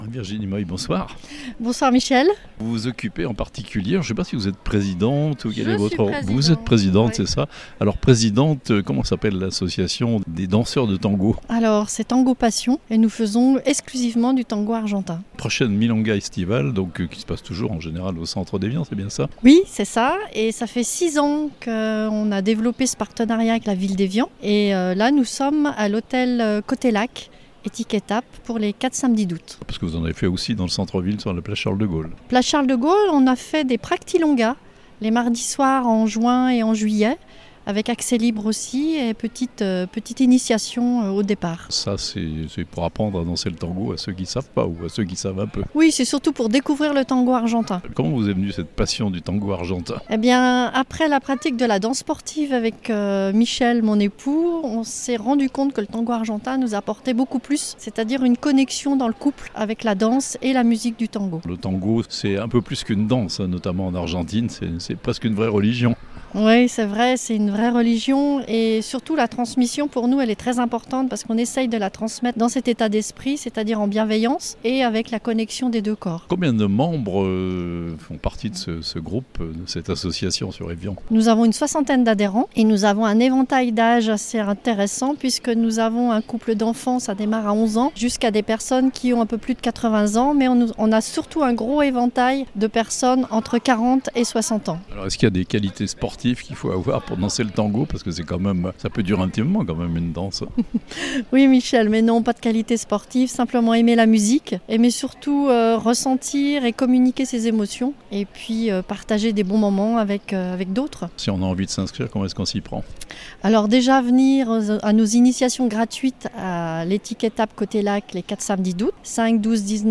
au micro La Radio Plus